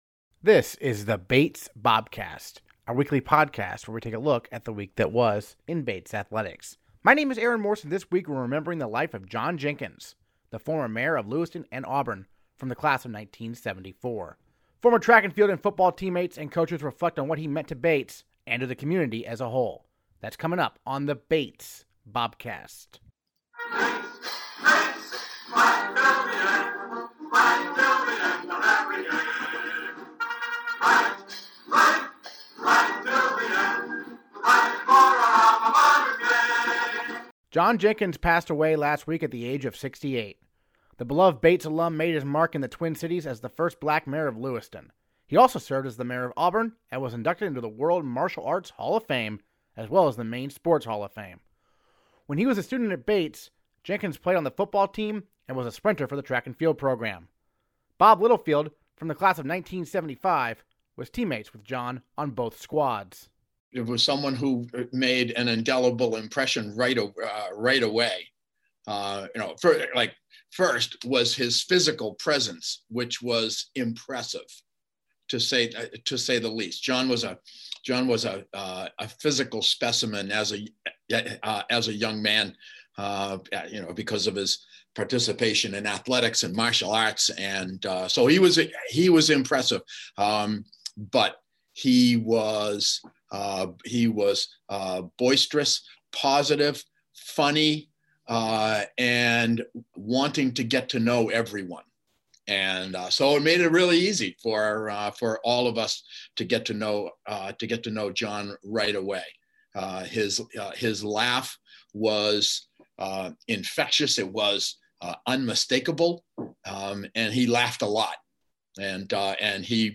Former track and field and football teammates and coaches reflect on what he meant to Bates and to the community as a whole.